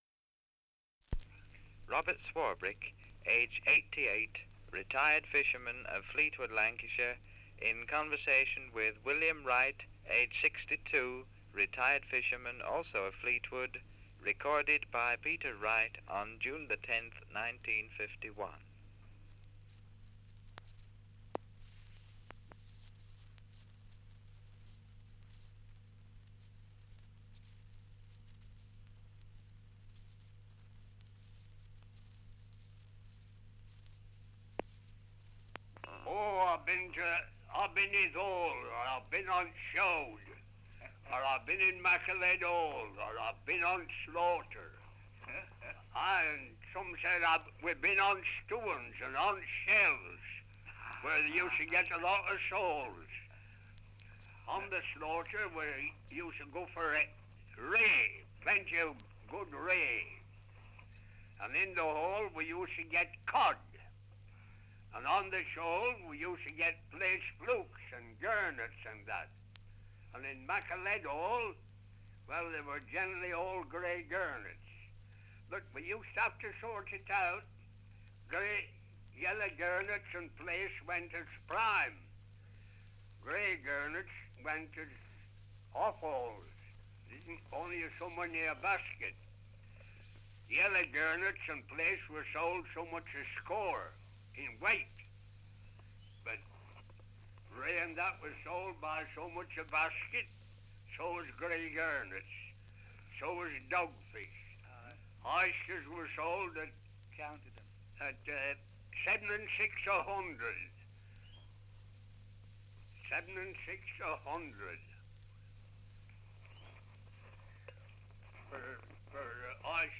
Survey of English Dialects recording in Fleetwood, Lancashire
78 r.p.m., cellulose nitrate on aluminium